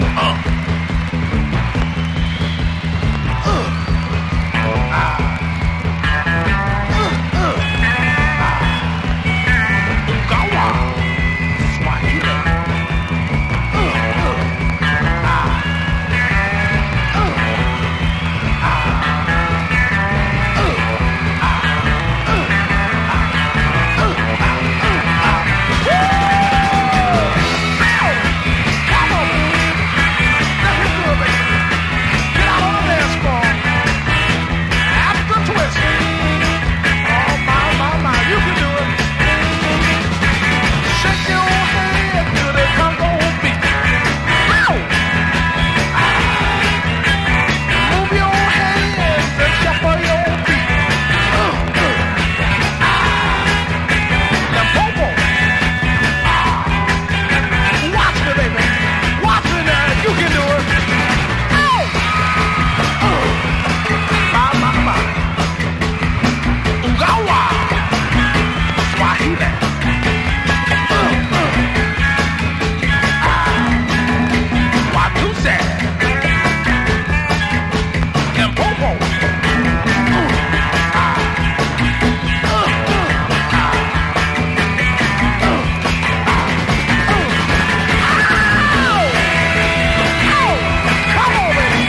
汗だくファンキー・ソウル/R&Bシャウター！